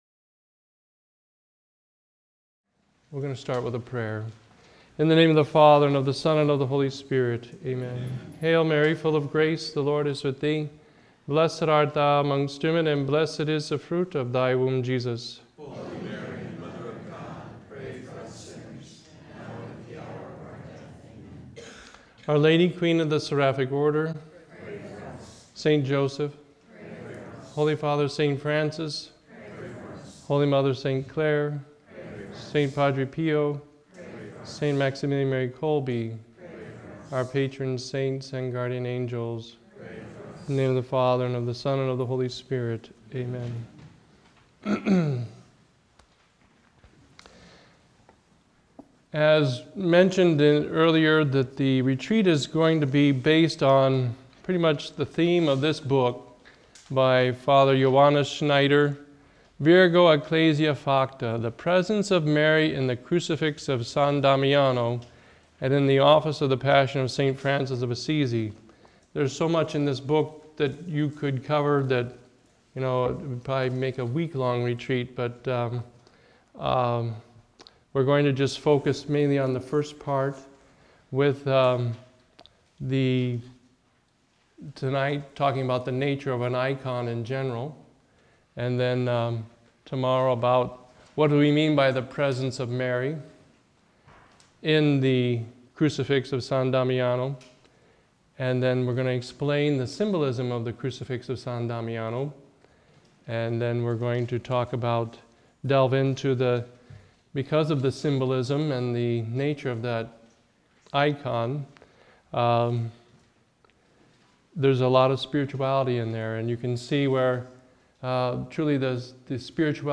The annual M.I.M. retreat for 2012.